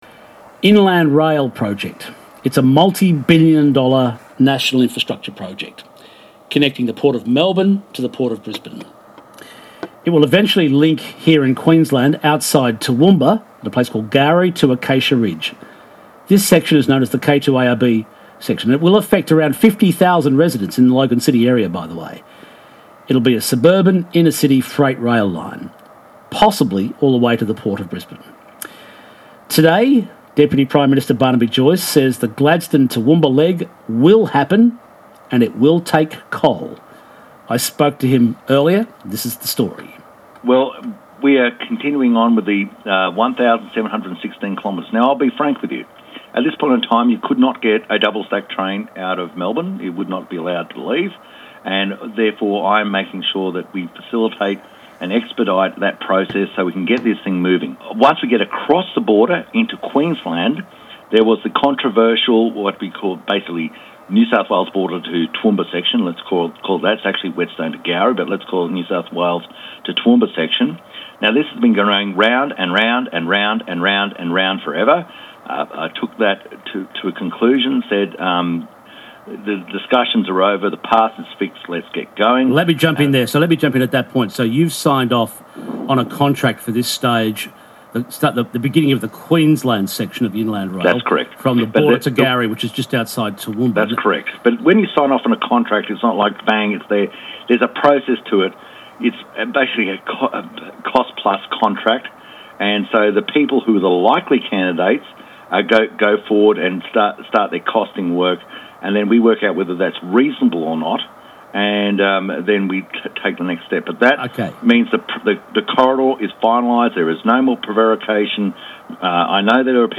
Interview
Deputy Prime Minister Barnaby Joyce. Topic: Inland Rail with particular reference to Queensland > https